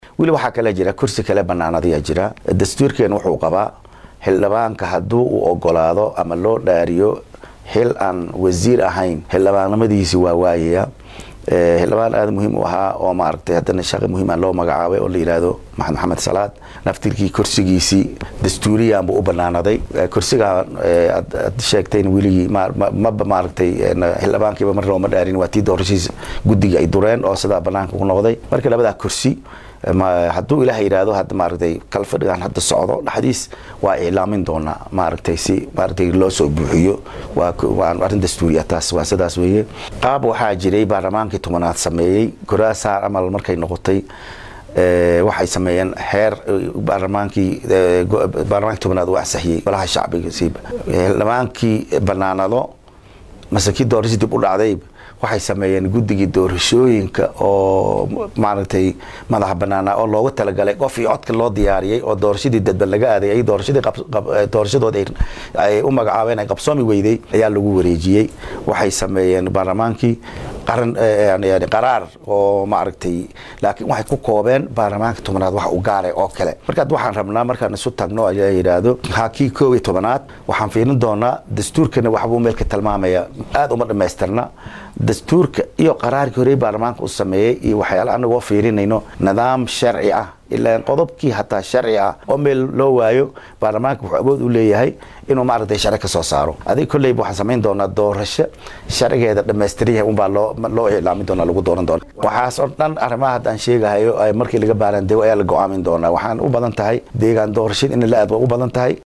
Guddoomiyaha Golaha Shacabka ee Baarlamaanka Federaalka ah ee Soomaaliya Sheekh Aadan Maxamad Nuur (Madoobe) ayaa ka hadlay mowqifkiisa ku aadan xirnaanshaha
Guddoomiyaha oo isaga oo ku sugan Magaalada Nairobi oo la hadlay qaar ka mid ah warbaahinnada Somaaliyeed ayaa sheegay in ka guddoomiye ahaan uu horay u caddeeyay mowqifkiisa, islamarkaana Mukhtaar Roobow ay xirtay dowladdii uu hoggaaminayay madaxweynihii hore ee Soomaaliya Maxamad Cabdullaahi Farmaajo.